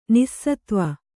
♪ nissatva